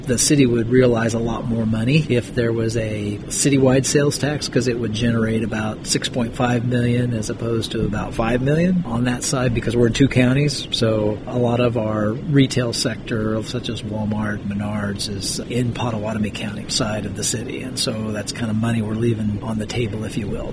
Fehr explains some of the reasoning behind a city sales tax.